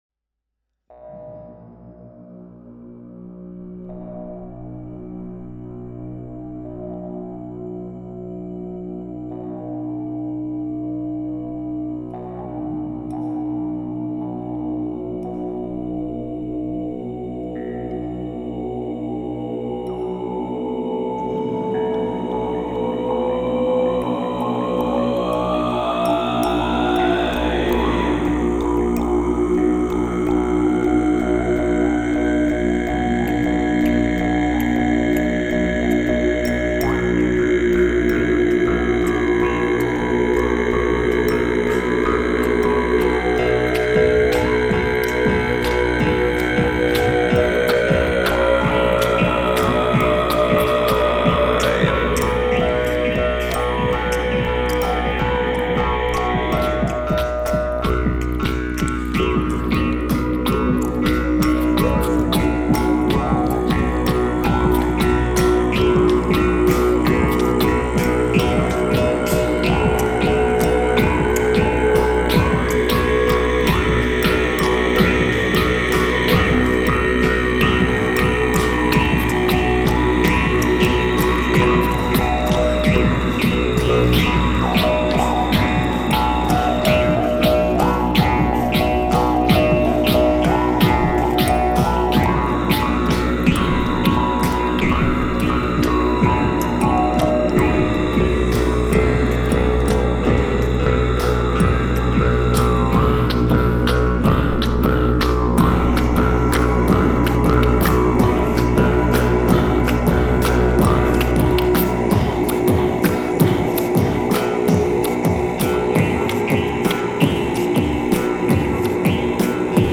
Mixage Binaural